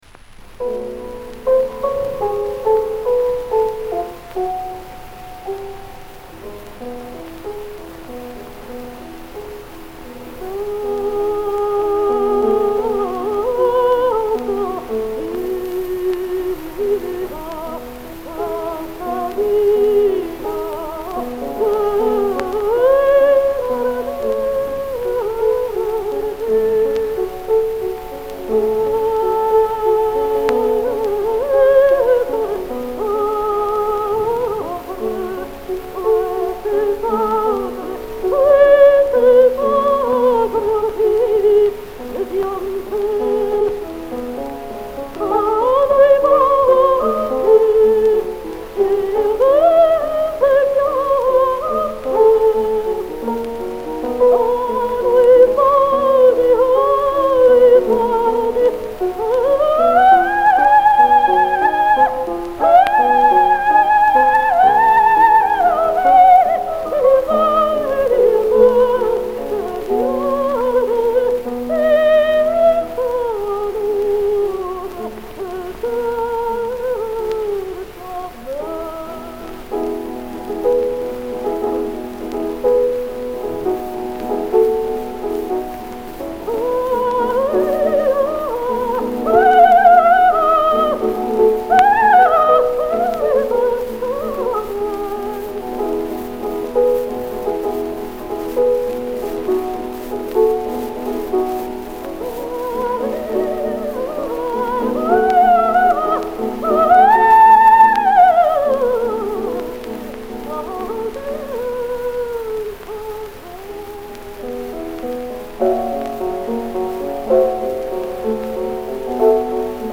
Крупнейшая певица 19 в., обладала виртуозной вок. техникой.